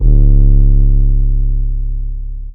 808 [TEC].wav